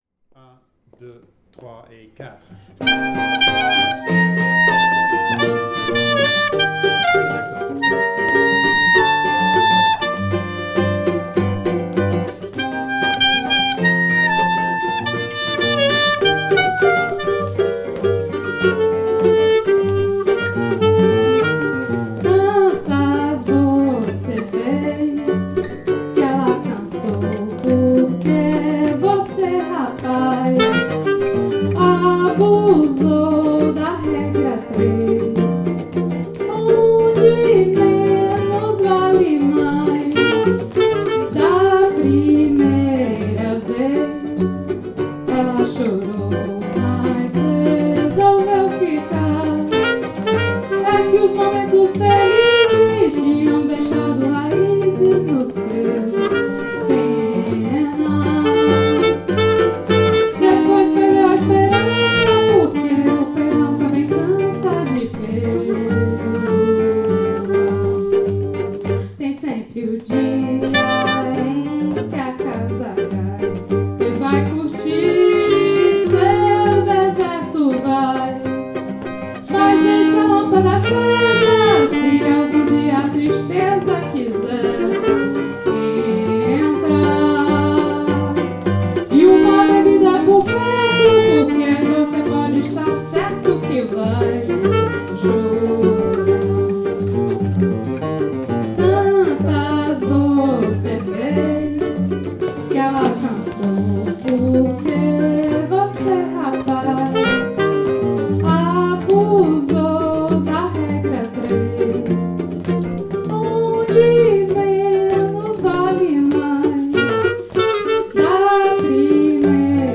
Rec atelier
Session sans percussions
Regra_3_sans_percus.mp3